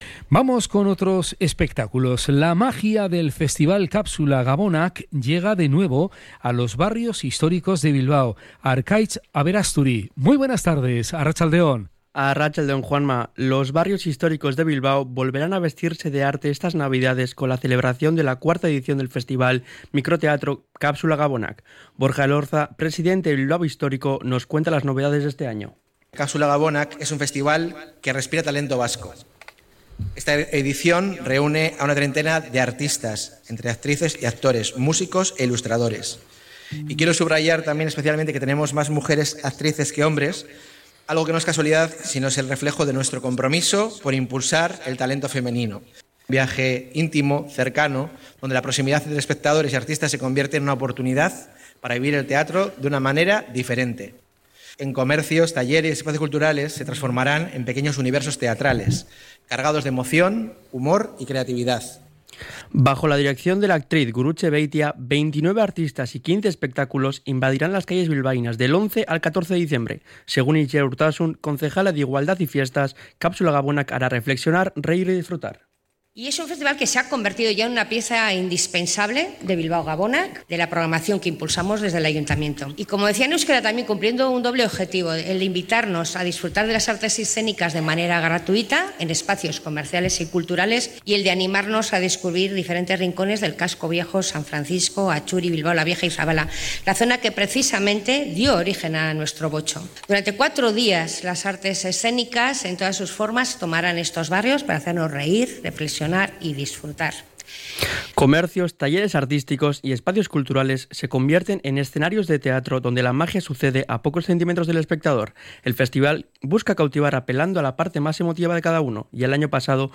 Cronica-Kapsula-Gabonak.mp3